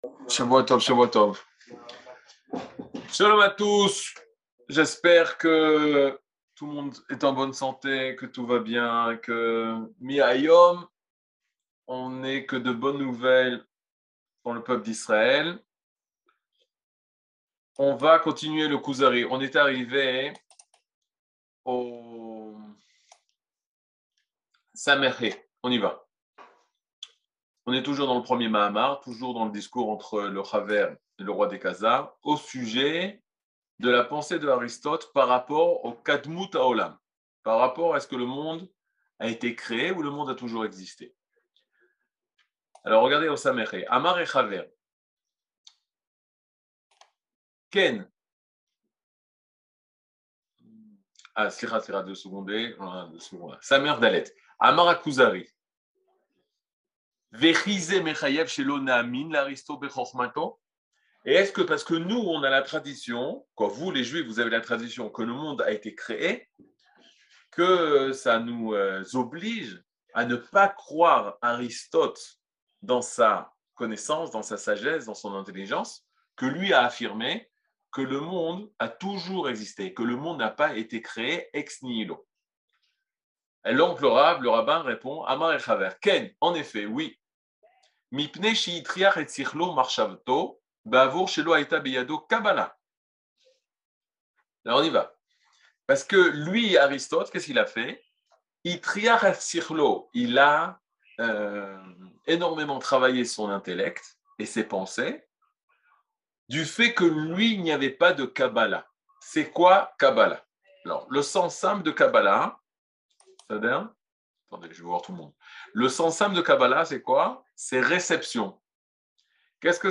Mini-cours